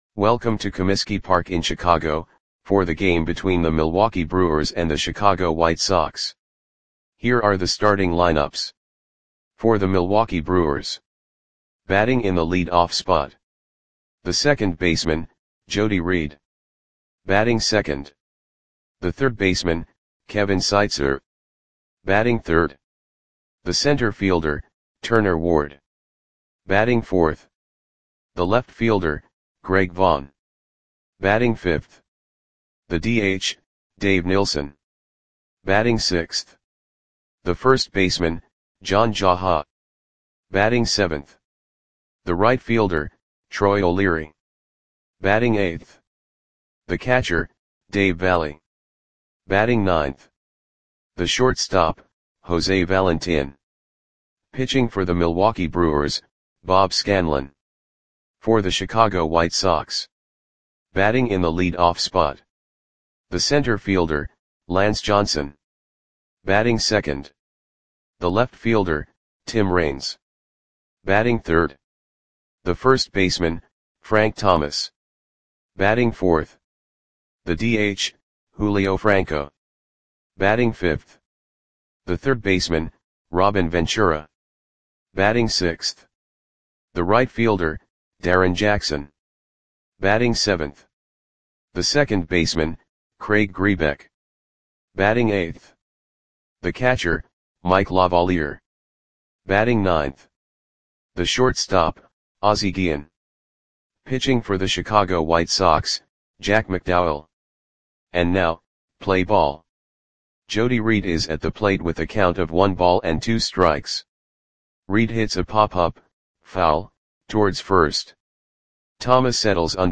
Audio Play-by-Play for Chicago White Sox on July 1, 1994
Click the button below to listen to the audio play-by-play.